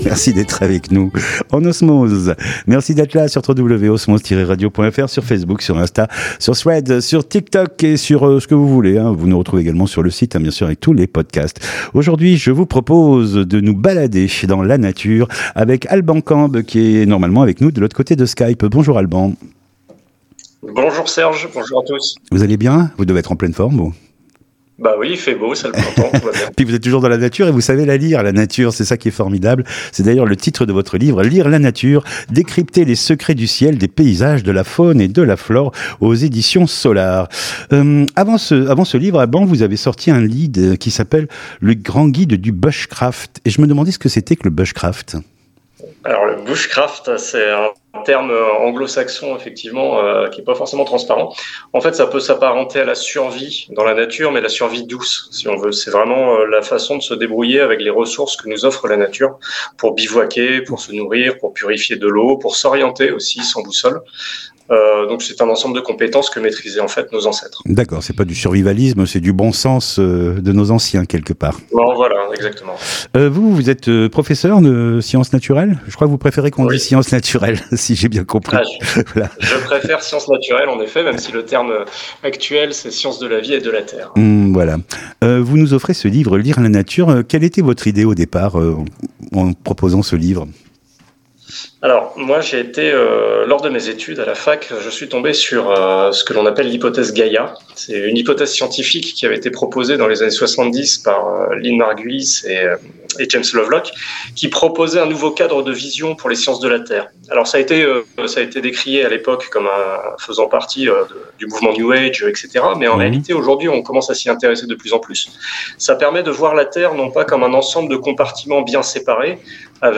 Culture/Loisirs Interviews courtes